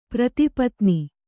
♪ prati patni